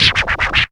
SLOW SCRATCH.wav